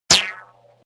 Rubber band sling shot.mp3